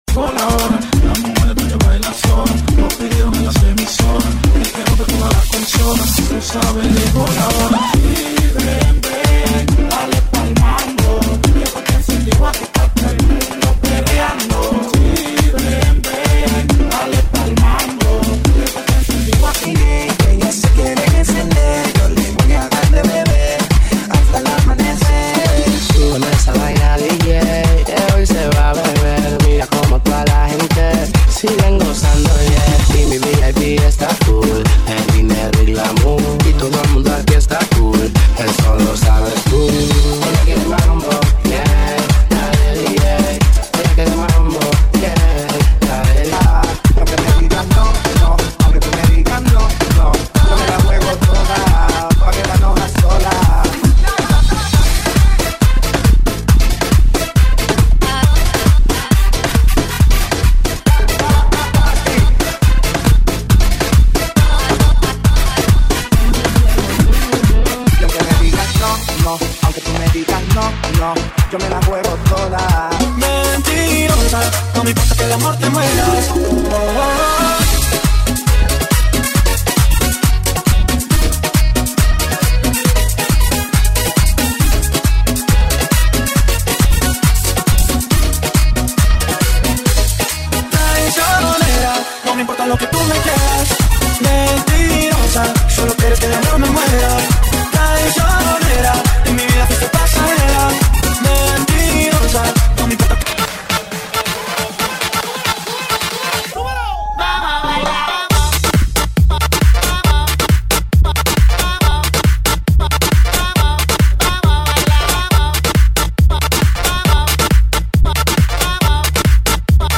GENERO: LATINO MAMBO MERENGUE ELETRO